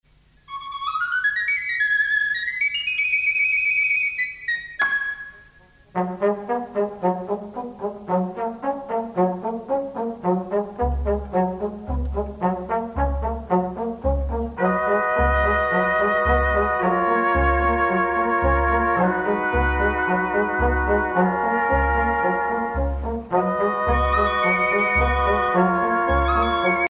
danse : sardane
Pièce musicale éditée